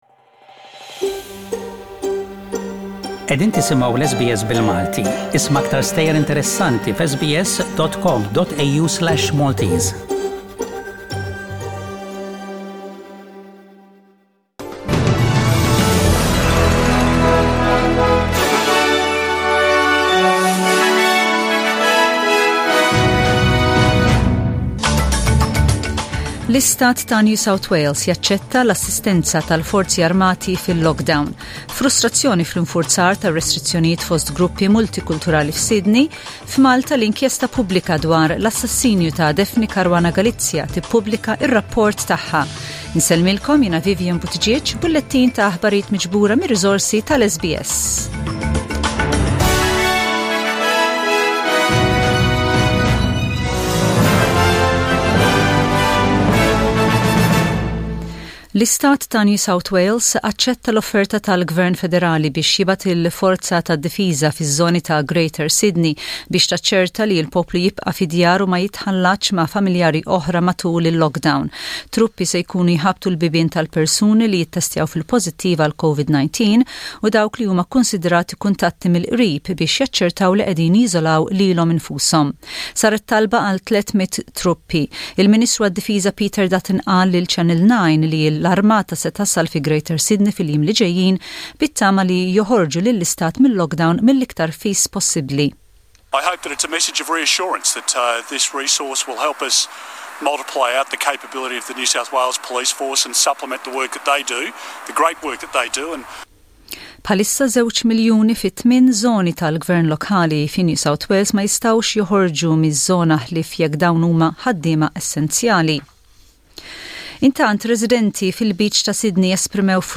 SBS Radio | News in Maltese: 30/07/21